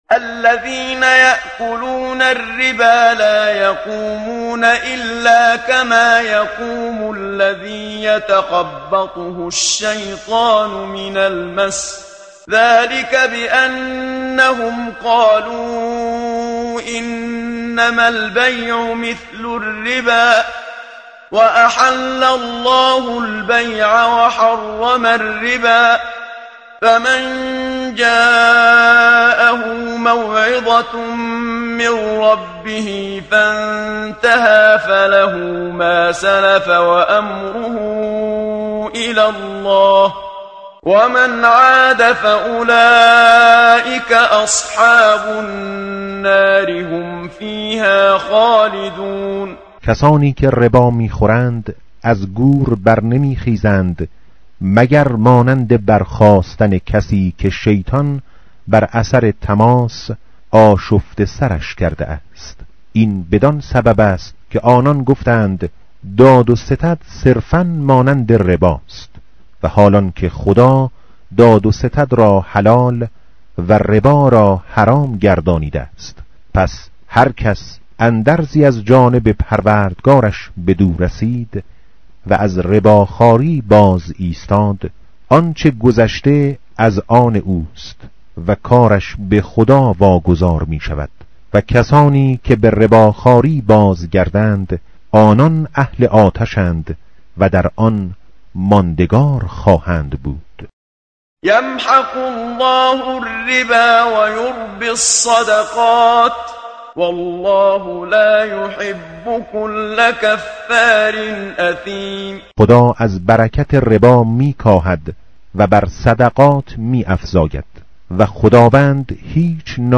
tartil_menshavi va tarjome_Page_047.mp3